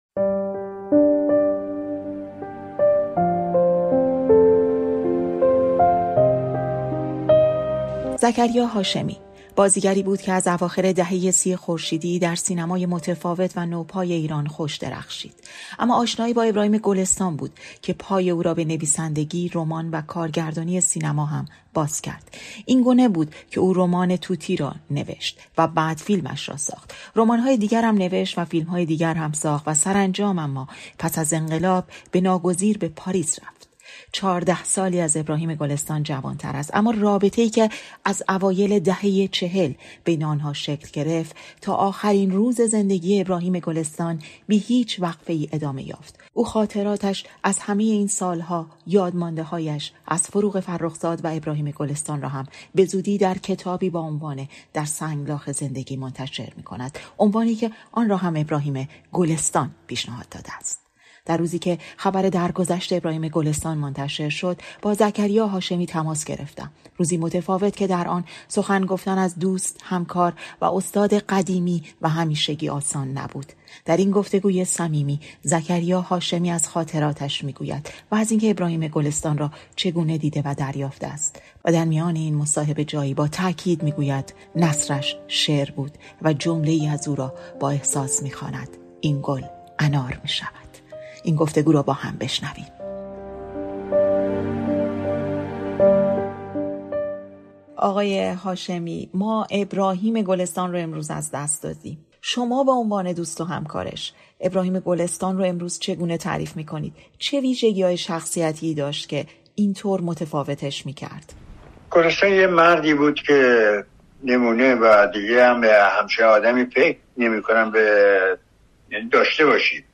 در این گفت‌وگوی صمیمی، زکریا هاشمی از خاطراتش از ابراهیم گلستان می‌گوید و از اینکه او را چگونه دیده و دریافته است. او تأکید می‌گوید که گلستان نثرش شعر بود.